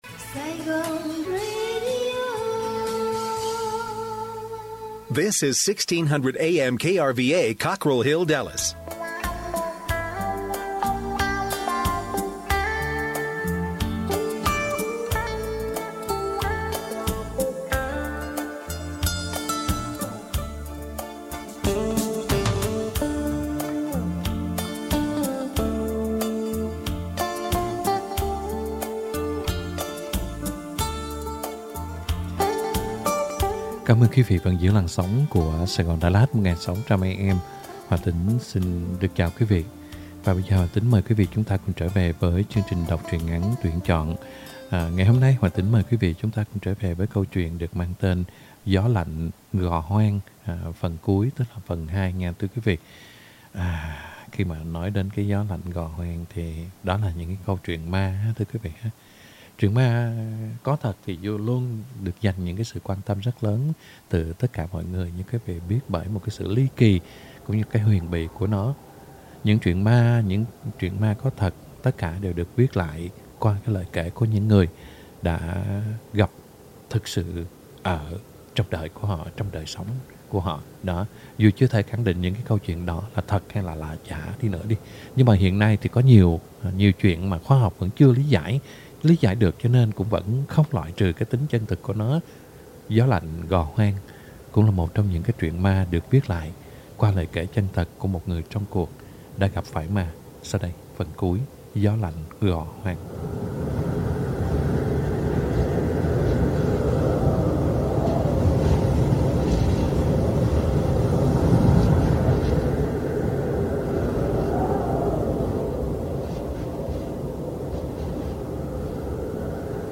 Home Đọc Truyện Ngắn = Gió lạnh gò hoang (2 end) - 10/12/2021 .